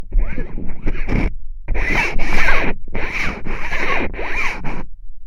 천막긁는.mp3